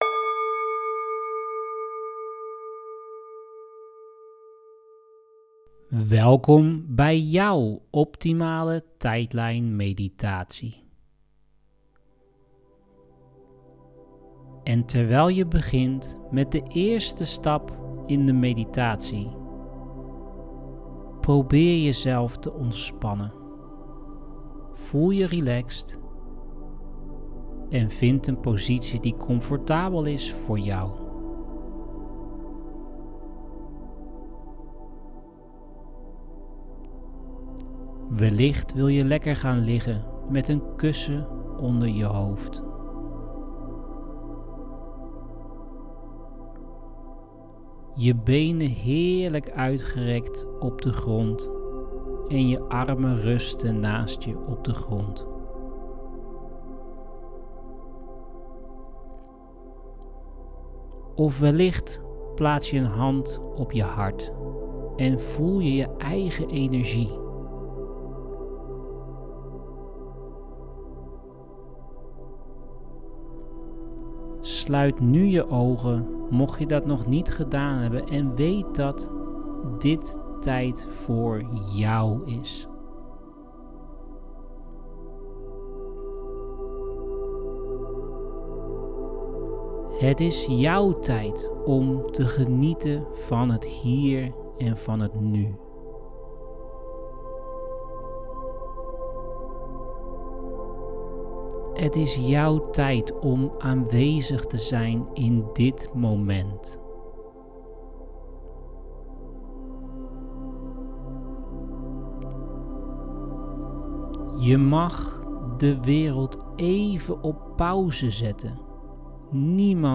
begeleide-tijdlijn-meditatie.mp3